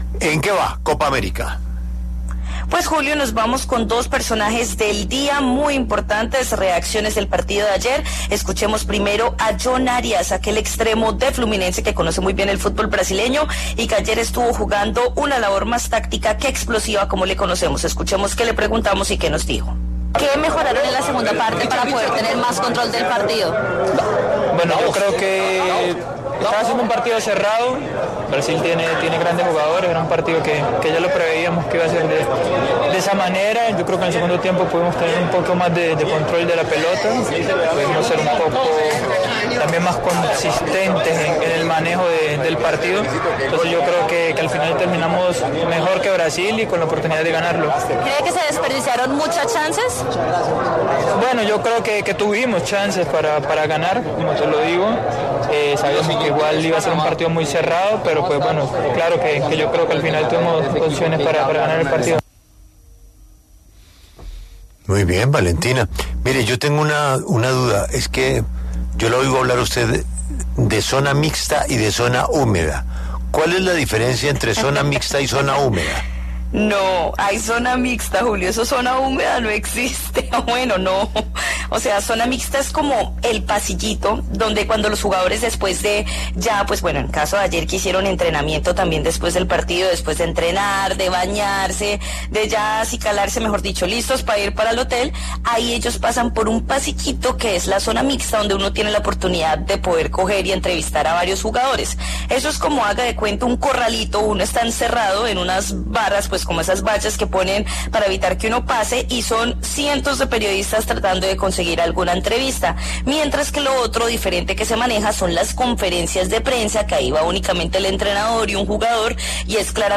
Tras el empate de la Selección Colombia ante Brasil en el último partido de la fase de grupos de la Conmebol Copa América Estados Unidos 2024, La W conversó con Jhon Arias sobre los resultados que dejó este encuentro.